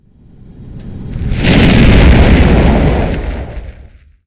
fire_explosion
burst explosion fire inferno sound effect free sound royalty free Sound Effects